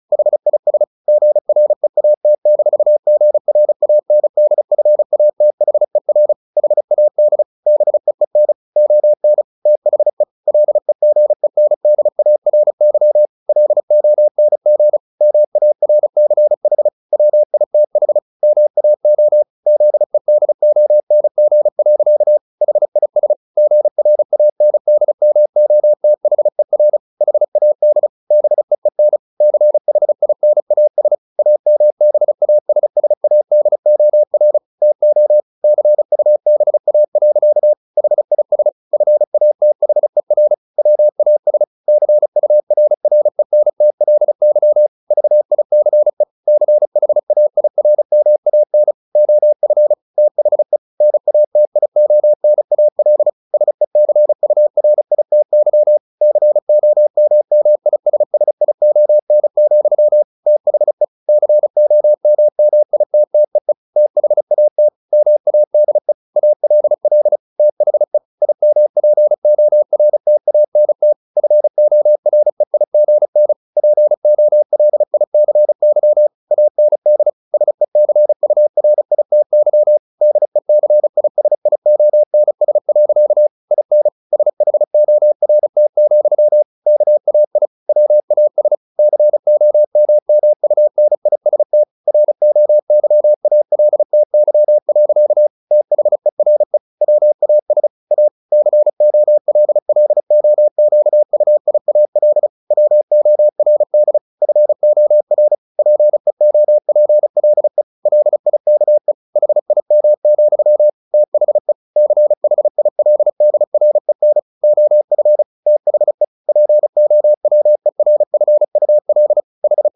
Never 35wpm | CW med Gnister